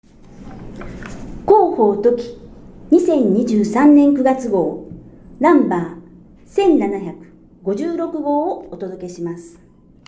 音声欄に表示があるものは、「声の広報」として音声で聞くことができます。